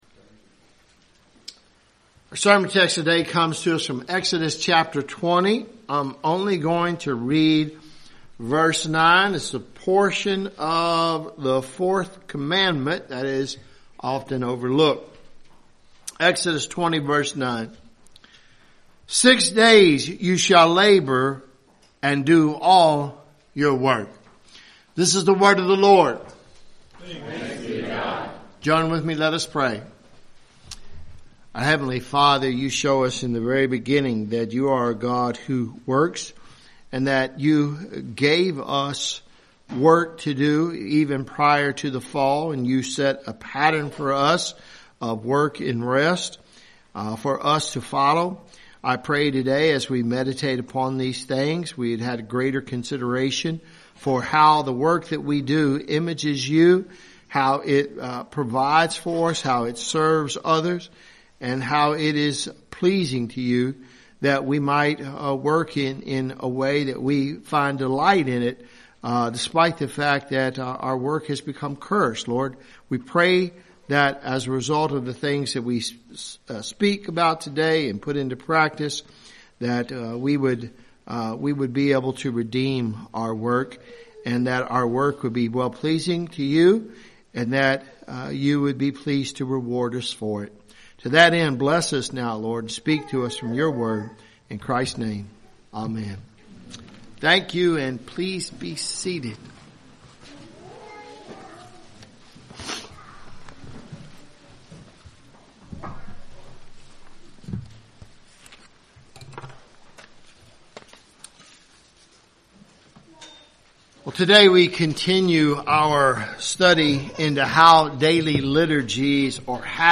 at Christ Covenant Presbyterian Church, Versailles, Ky.